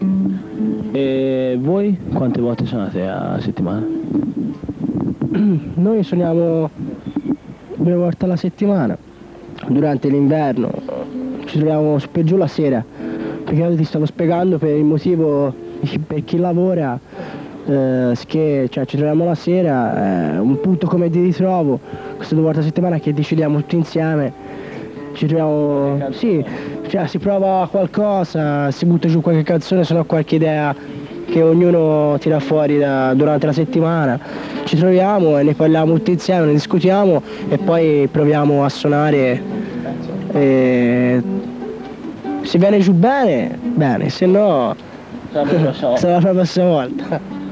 MusicaDroga Intervista.
monologo tre